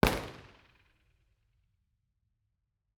IR_EigenmikeHHL2_processed_Bformat.wav